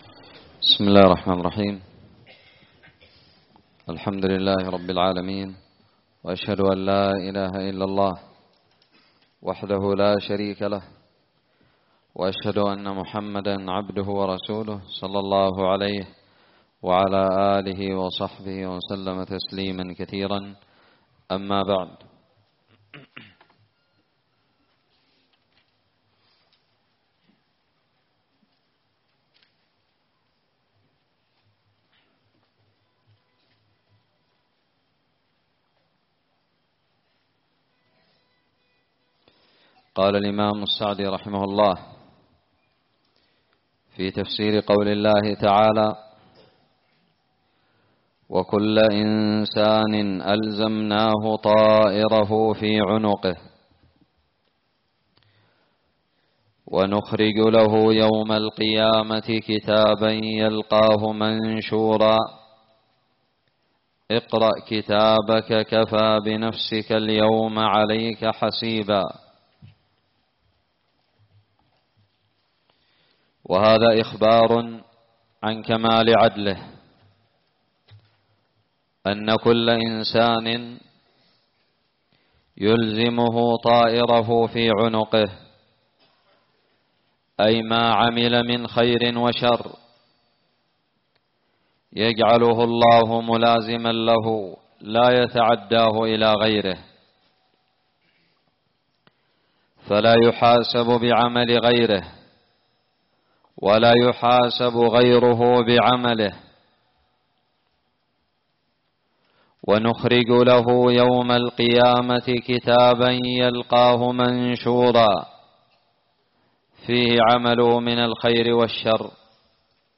الدرس الرابع من تفسير سورة الإسراء
ألقيت بدار الحديث السلفية للعلوم الشرعية بالضالع